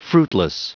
Prononciation du mot fruitless en anglais (fichier audio)
Prononciation du mot : fruitless